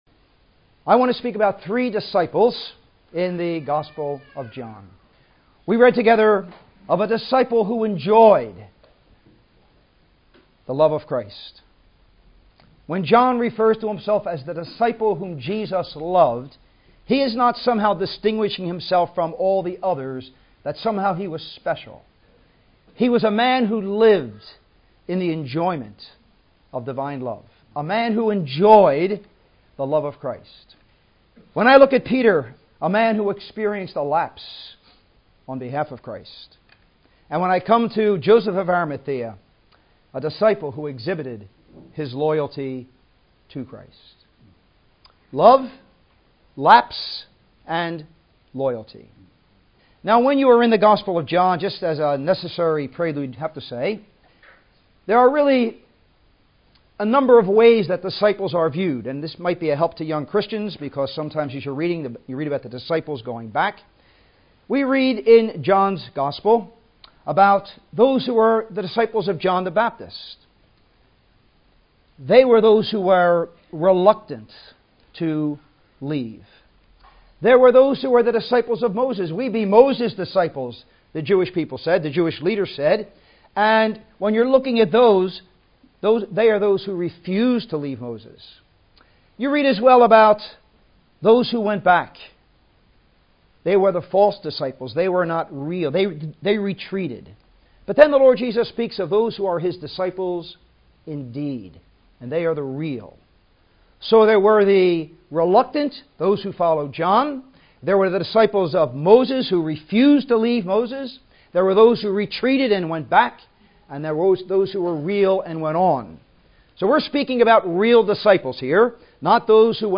John the apostle, marked by love for Christ. Peter, marked by a sad lapse in devotion, as he followed at a distance. Joseph of Arimathea who was marked by loyalty to Christ in the hour of His death. Following Christ might mean burying your future…but a resurrected present will more than compensate (Message preached April 6th 2015)